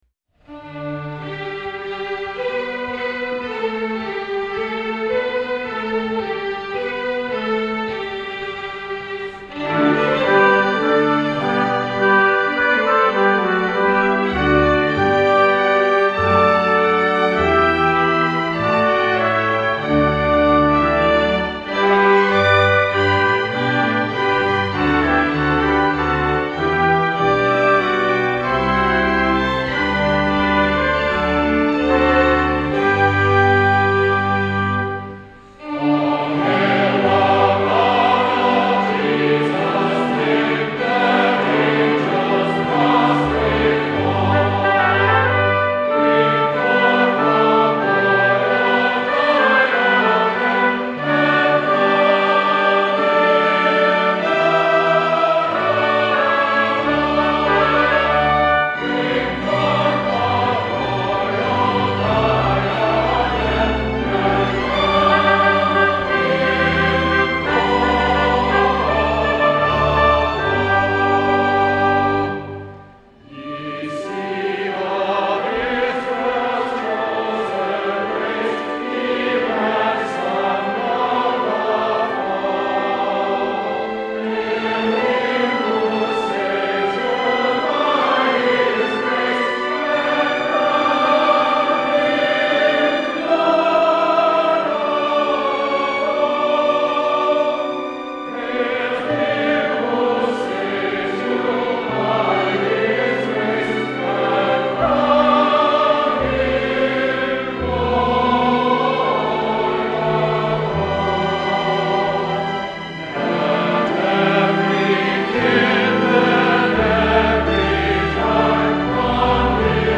Hymn Arrangements